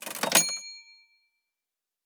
Cash Register.wav